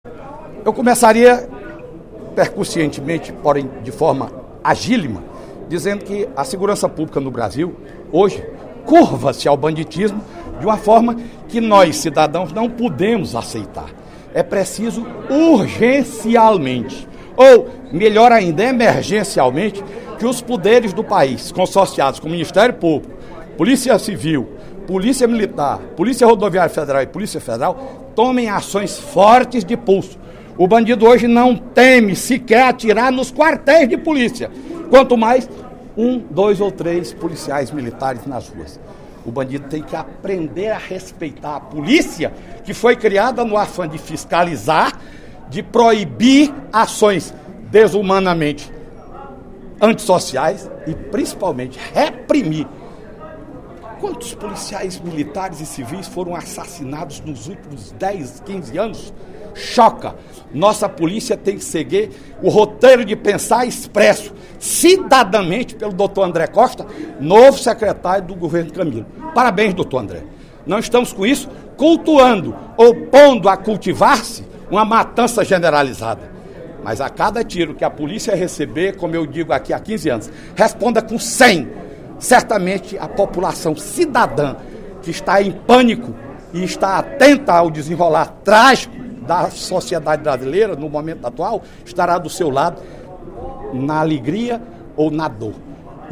O deputado Fernando Hugo (PP) defendeu, no primeiro expediente da sessão plenária desta terça-feira (07/02), a união de forças para solucionar o problema da segurança pública pelo qual atravessa o Brasil.